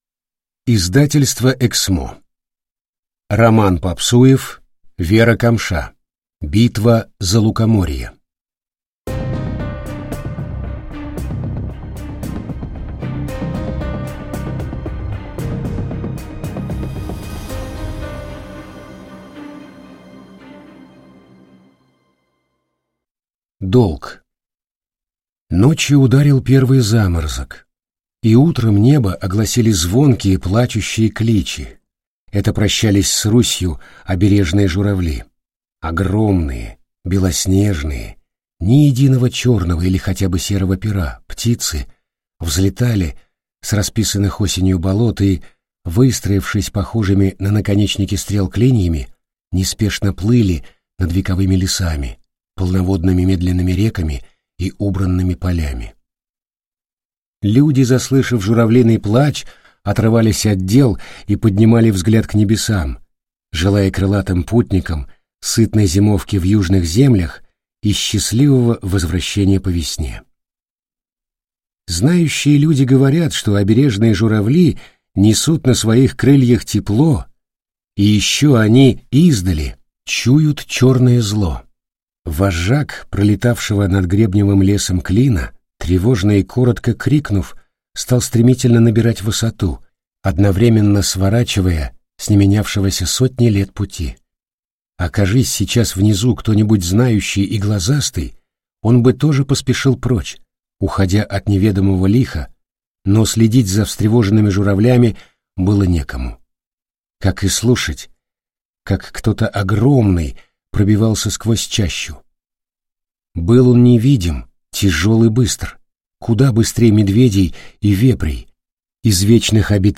Аудиокнига Битва за Лукоморье. Книга 1 | Библиотека аудиокниг